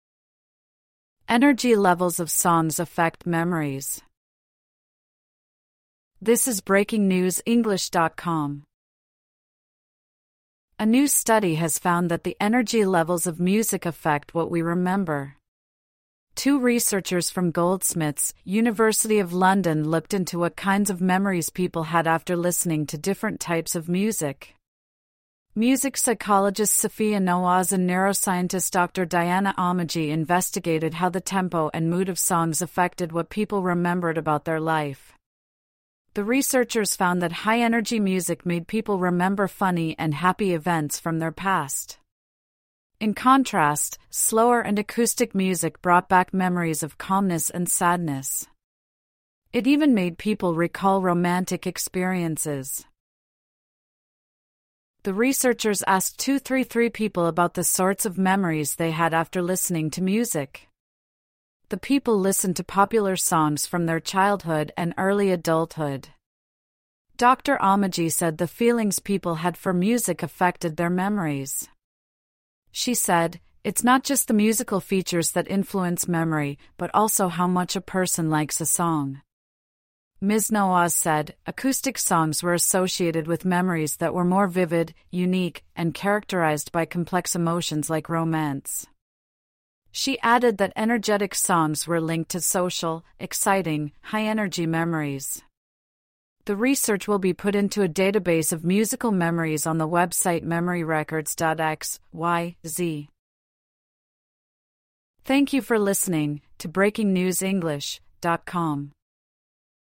AUDIO(Normal)